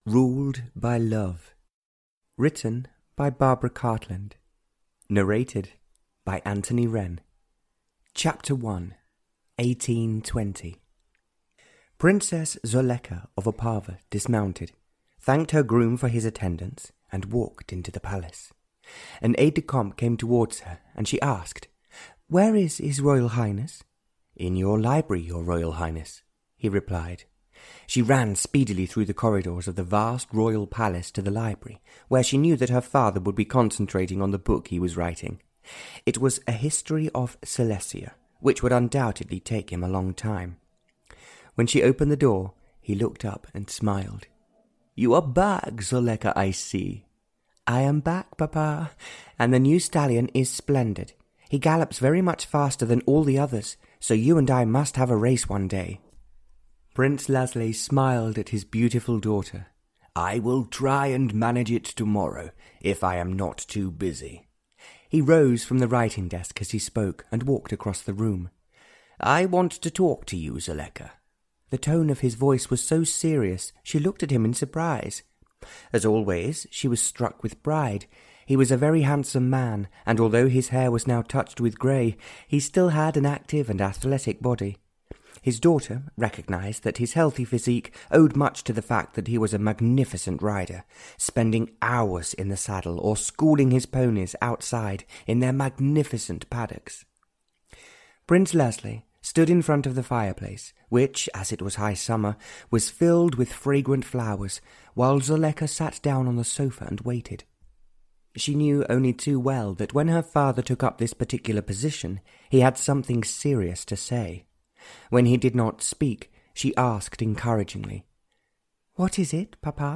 Audio knihaRuled By Love (Barbara Cartland’s Pink Collection 55) (EN)
Ukázka z knihy